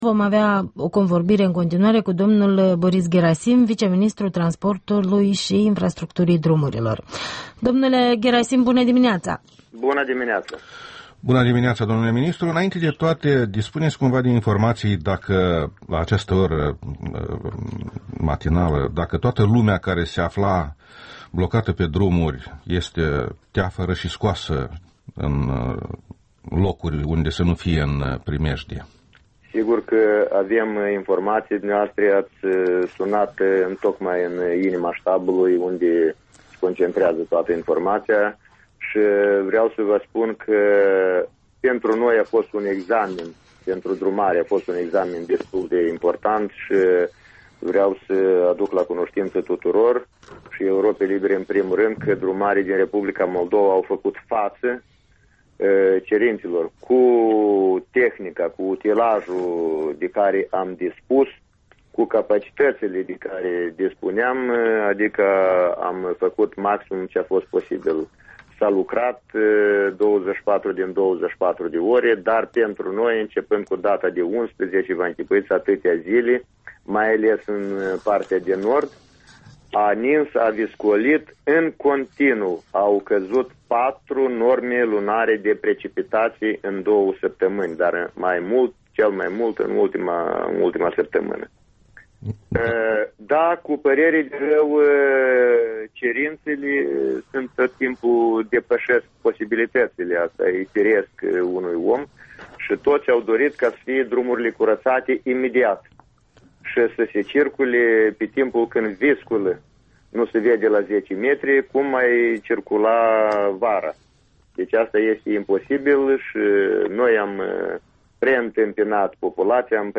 Interviul dimineții la REL: cu Boris Gherasim, ministrul adjunct al transporturilor